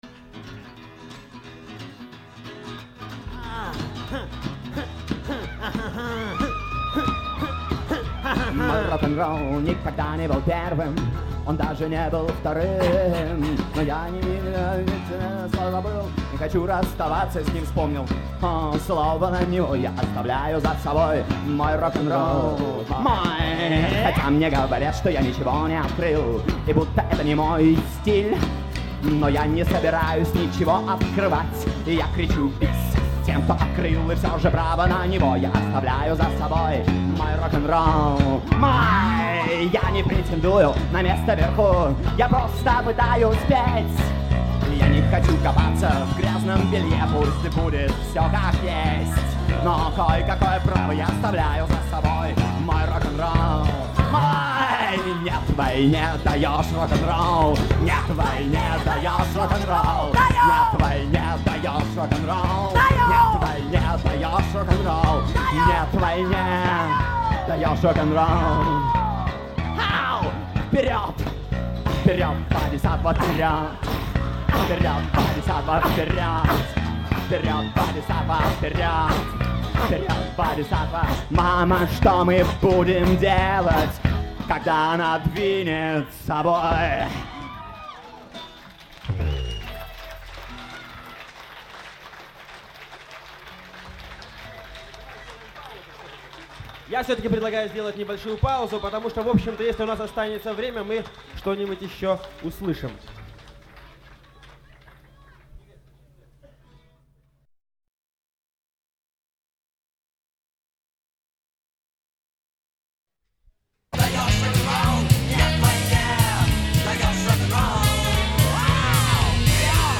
в г.Ленинграде (1996)